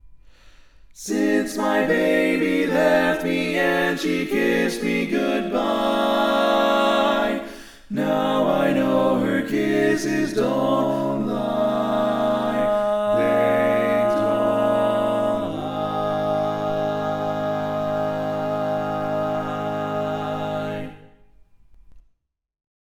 Type: Barbershop